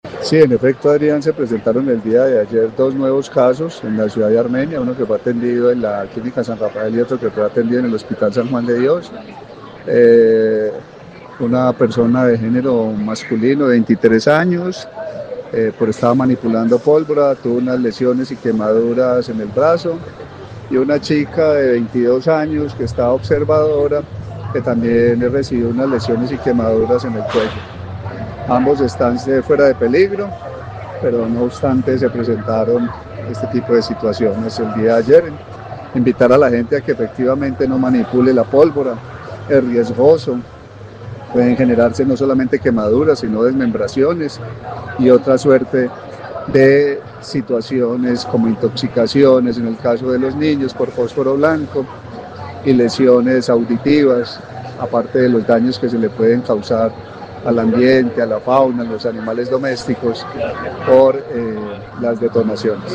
Carlos Alberto Gómez, secretario de salud del Quindío